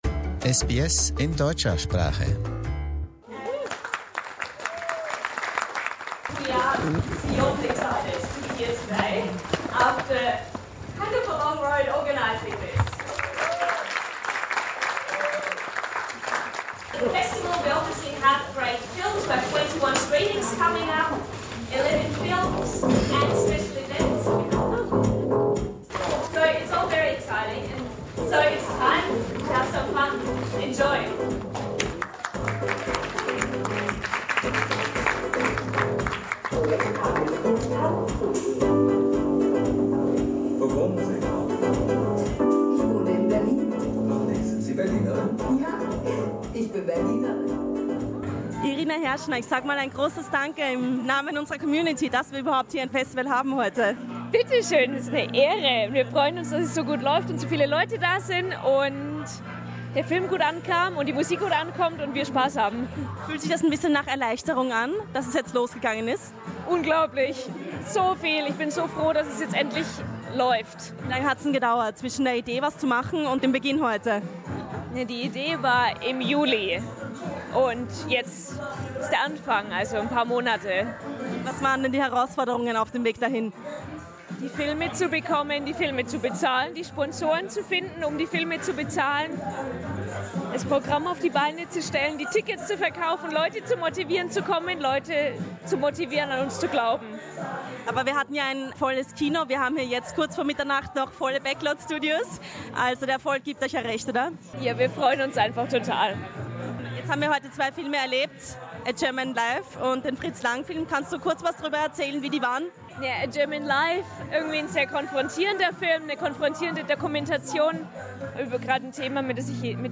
SBS hat sich unter die Gäste der Eröffnungsnacht gemischt.